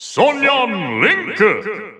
The announcer saying Young Link's name in Korean releases of Super Smash Bros. Ultimate.
Young_Link_Korean_Announcer_SSBU.wav